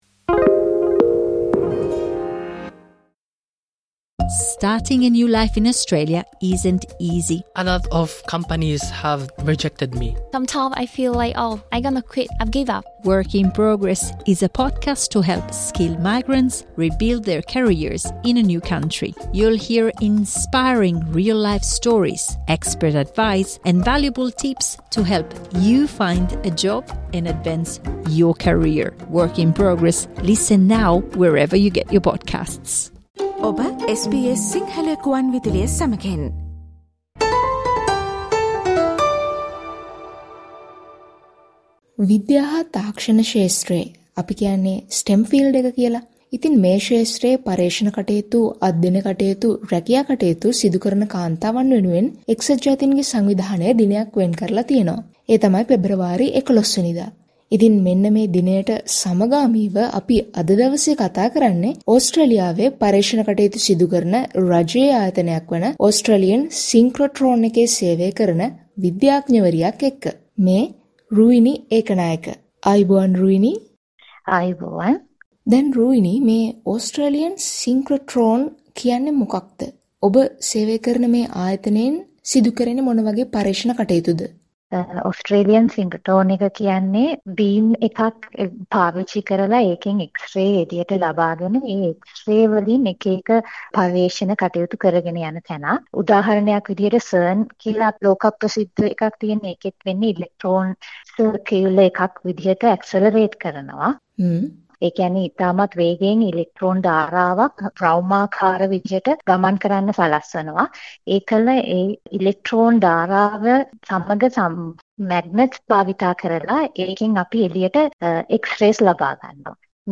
එක්සත් ජාතීන්ගේ සංවිධානය විසින් පෙබරවාරි 11වන දා International Day of Women and Girls in Science ලෙස නම් කර තිබෙනවා. ඒ විද්‍යා සහ තාක්ෂණ ක්ෂේත්‍රයේ නිරත කාන්තා පිරිස වෙනුවෙනුයි. ඊට සමගාමිව SBS සිංහල සේවය විසින් ඕස්ට්‍රේලියාවේ පර්යේෂණ කටයුතුවල නියැලෙන විද්‍යාඥවරියක සමඟ කතාබහක නිරත වුණා.